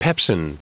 Prononciation du mot pepsin en anglais (fichier audio)
Prononciation du mot : pepsin